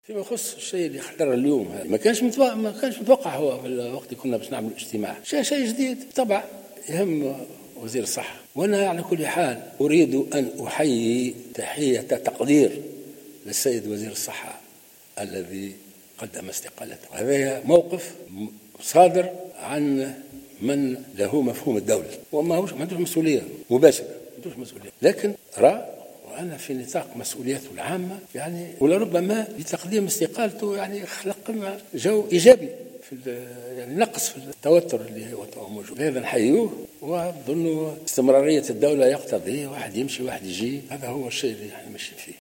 قال رئيس الجمهورية الباجي قايد السبسي على هامش انعقاد مجلس الأمن اليوم الإثنين 11 مارس 2019 إنه يتوجه بتحية تقدير لوزير الصحة عبد الرؤوف الشريف الذي استقال رغم أنه لم يكن له مسؤولية مباشرة في حادثة وفاة الرضع بمستشفى الرابطة.